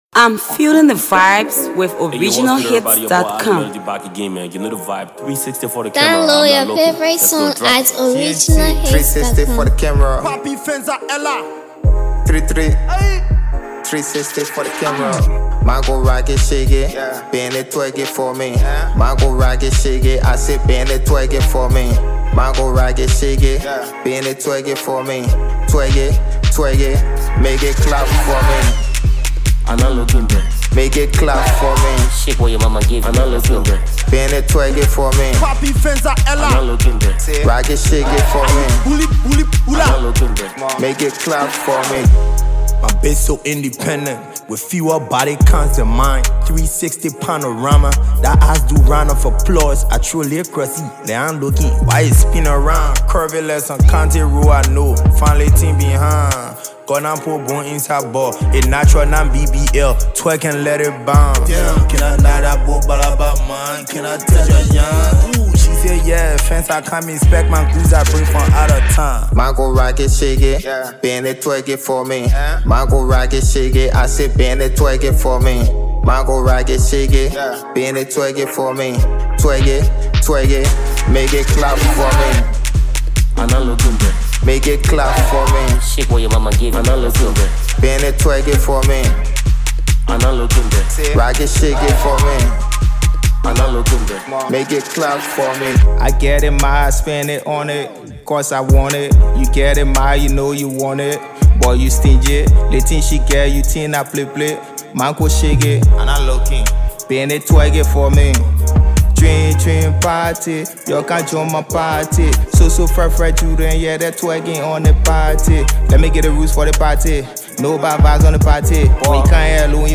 catchy, curated banger